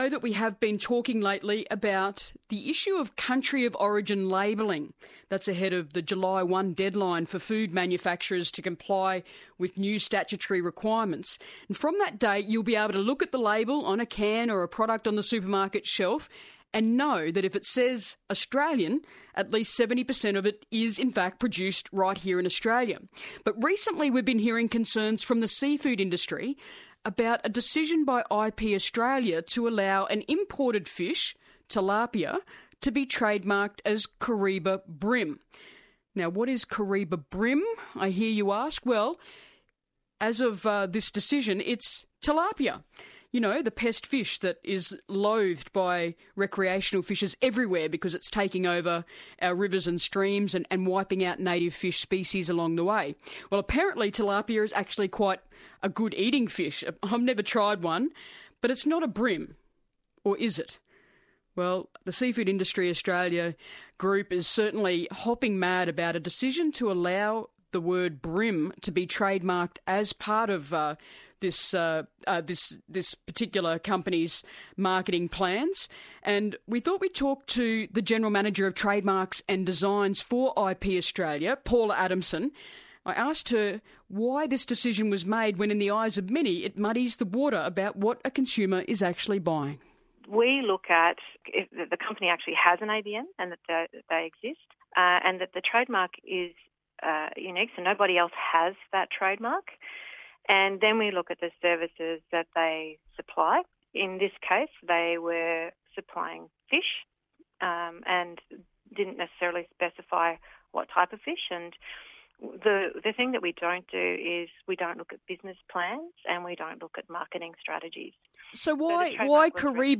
Karumba Bream Interview (ABC)